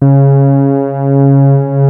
P.5 C#4 4.wav